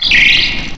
cry_not_klink.aif